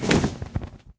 wings4.ogg